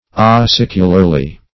[1913 Webster] A*cic"u*lar*ly , adv..